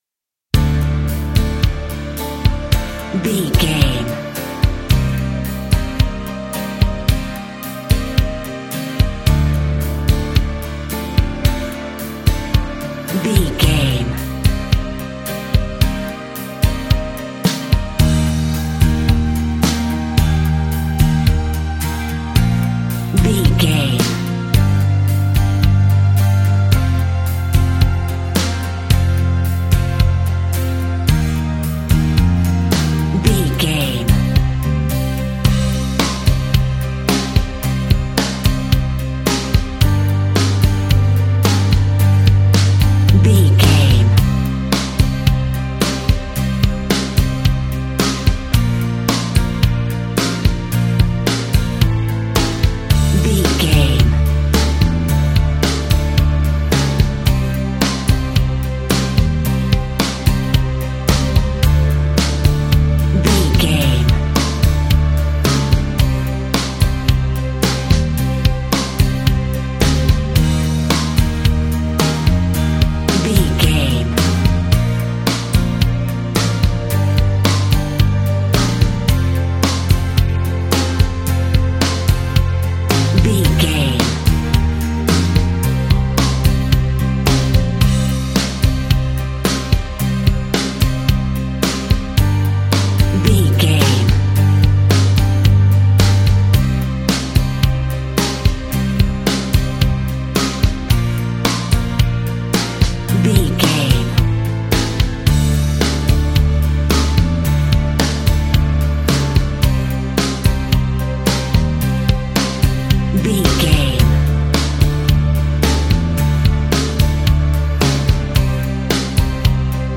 Aeolian/Minor
energetic
uplifting
cheesy
instrumentals
guitars
bass
drums
organ